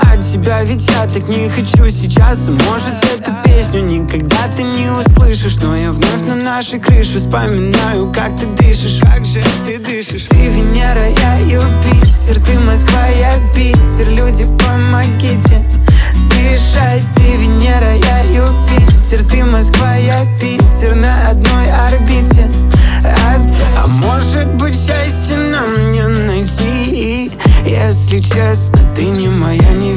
Жанр: Поп музыка / Танцевальные / Русский поп / Русские